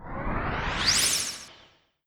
Quick Rising Sound_modified.wav